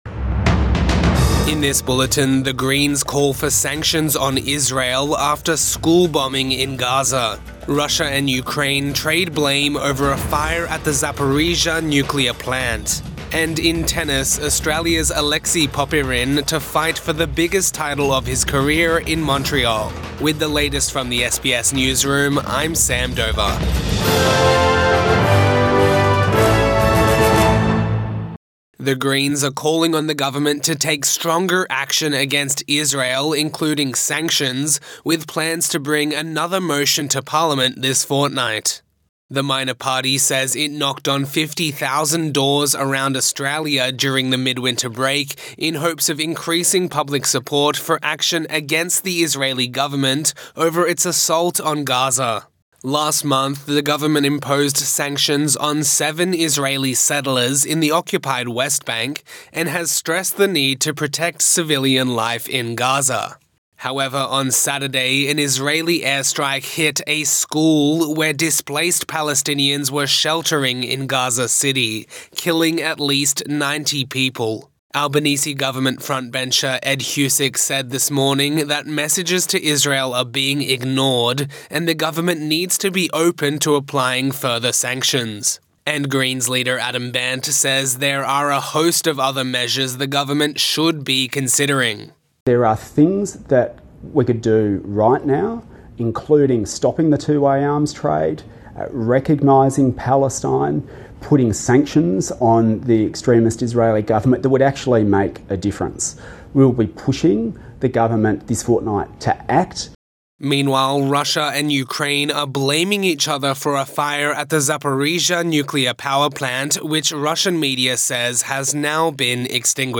Evening News Bulletin 12 August 2024